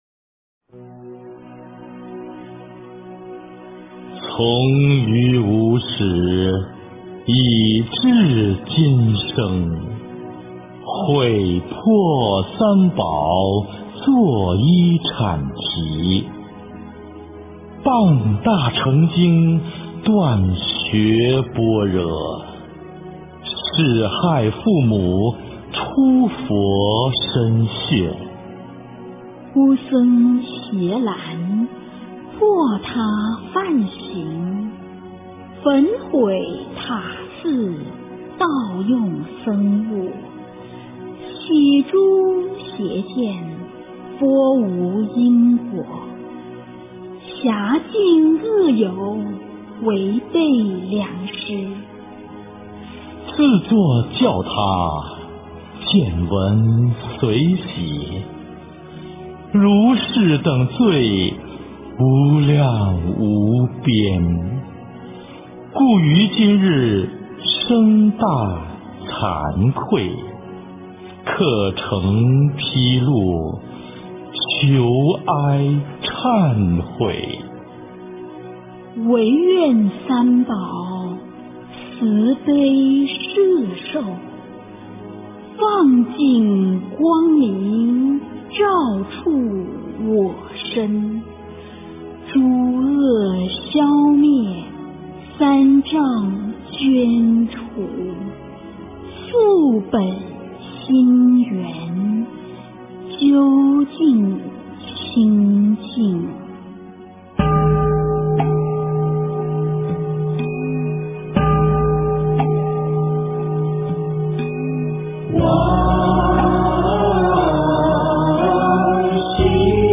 佛前忏悔发愿文--居士团 经忏 佛前忏悔发愿文--居士团 点我： 标签: 佛音 经忏 佛教音乐 返回列表 上一篇： 南无大愿地藏王菩萨--地藏忏 下一篇： 南无大悲观世音--男声缓慢版 相关文章 召请各类孤魂同赴法会--群星 召请各类孤魂同赴法会--群星...